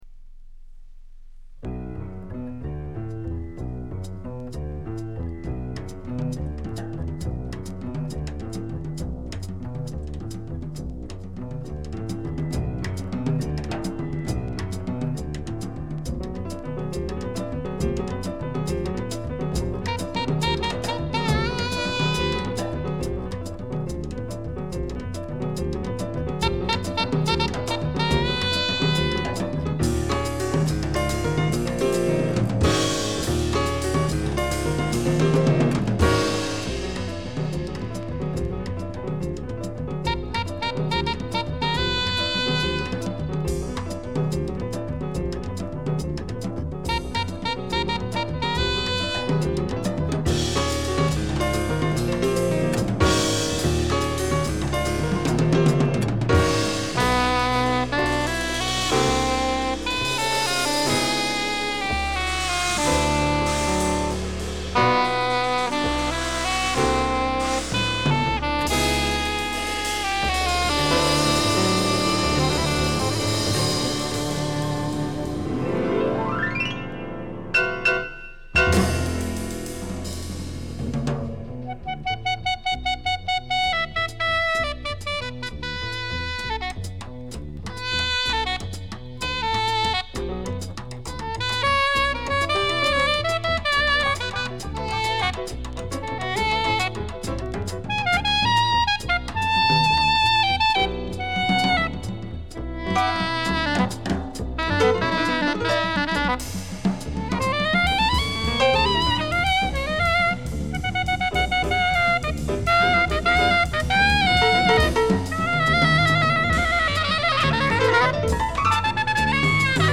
Geneva, Aquarius Studio - 1977
sax soprano & flûte